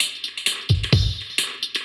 Index of /musicradar/dub-designer-samples/130bpm/Beats
DD_BeatC_130-02.wav